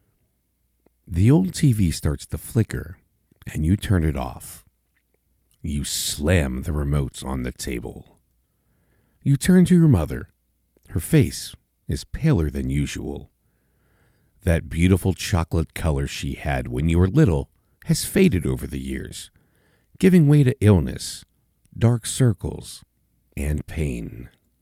Audiobook Sample - Strong, Confident